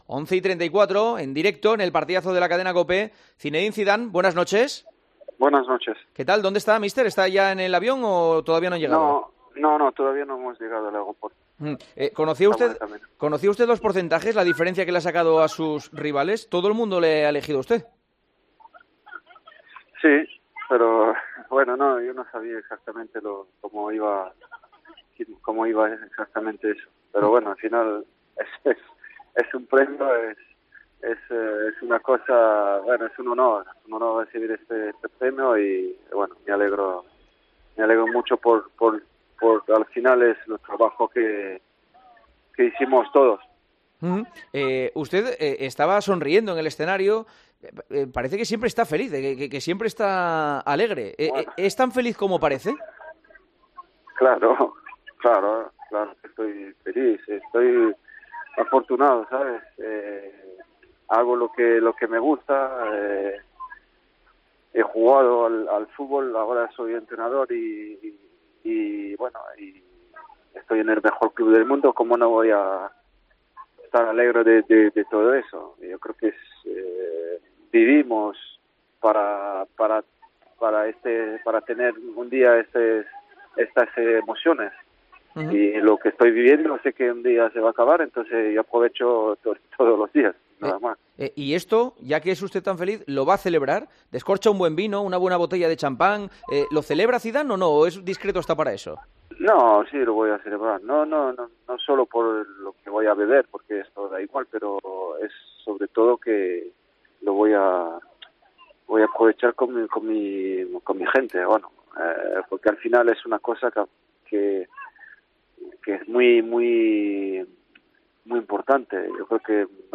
Entrevista en El Partidazo de COPE